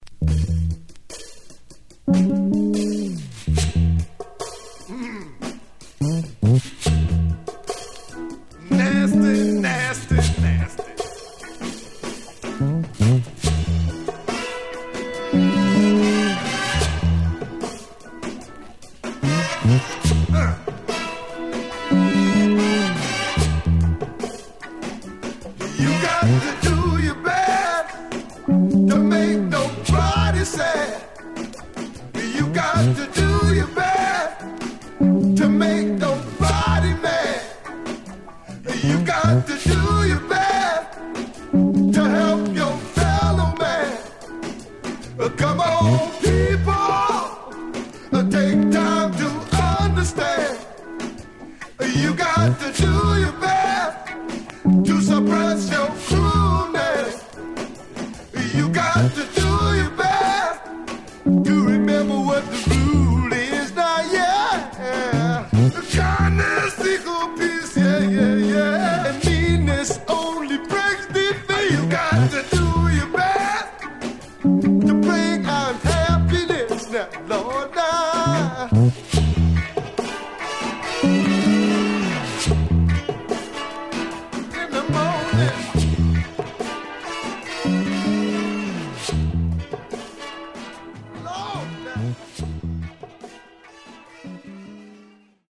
Stereo/mono Mono
Folk